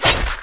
hookhit.wav